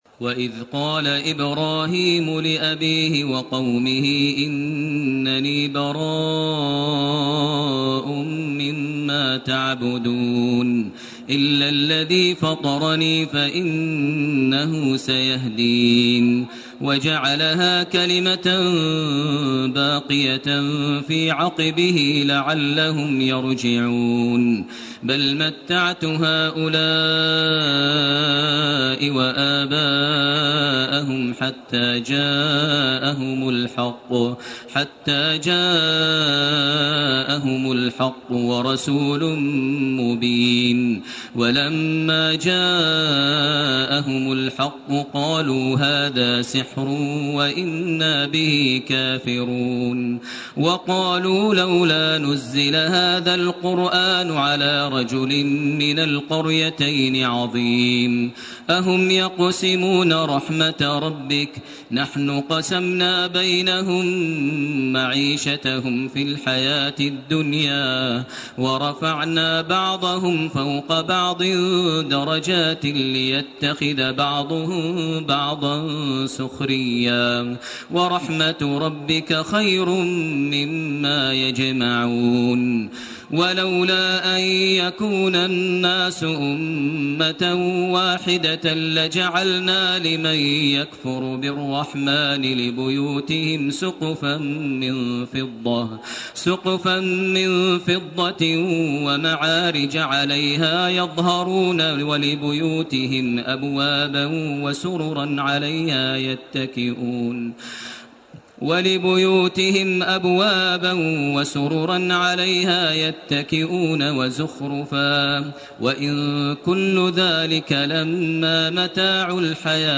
الزخرف من 26 الي اخرها سورة الدخان سورة الجاثية > تراويح ١٤٣٢ > التراويح - تلاوات ماهر المعيقلي